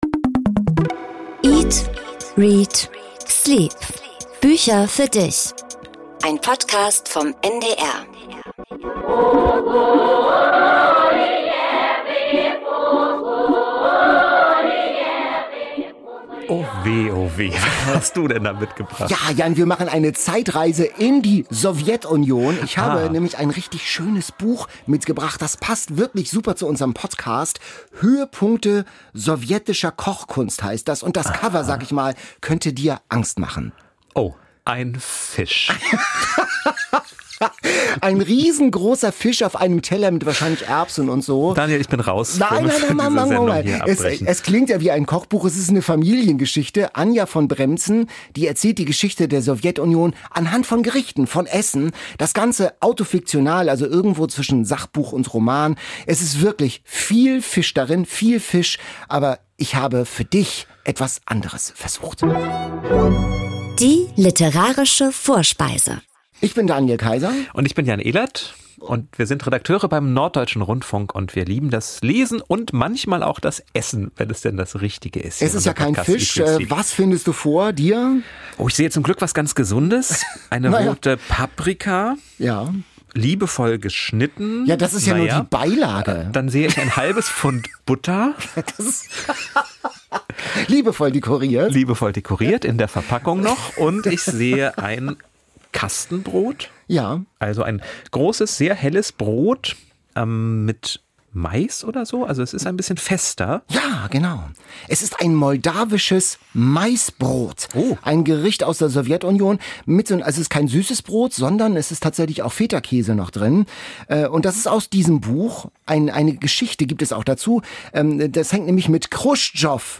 00:31:23 Interview mit Rebecca Gablé